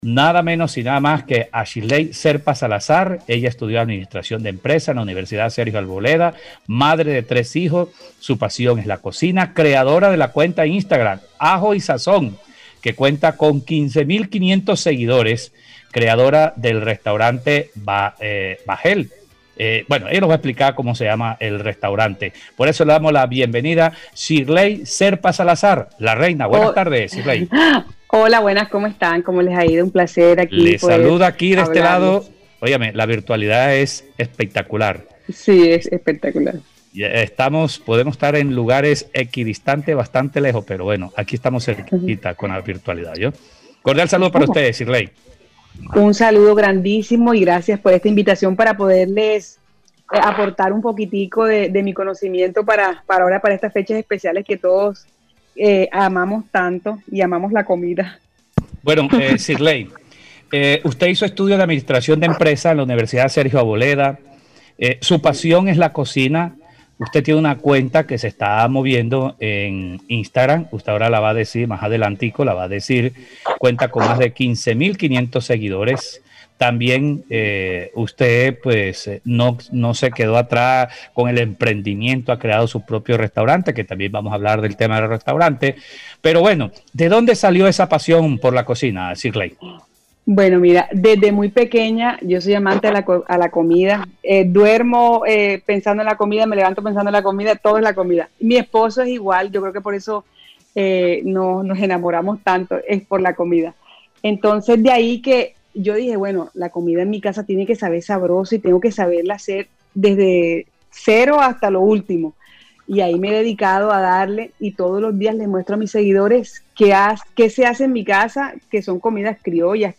(Entrevista) Recetas prácticas y económicas para preparar en diciembre